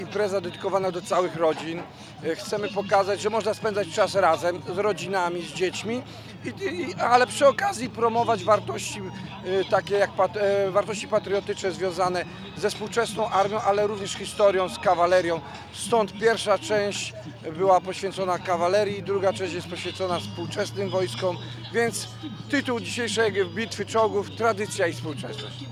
Mogłoby się wydawać, że wydarzenie jest skierowane do starszej publiczności, jednak, jak zaznacza Dowódca 15. Giżyckiej Brygady Zmechanizowanej im. Zawiszy Czarnego Generał Jarosław Gromadziński, jest to świetna impreza dla całych rodzin.